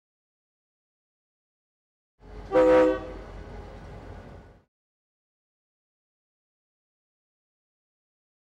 Train Horn Blow, Medium Perspective.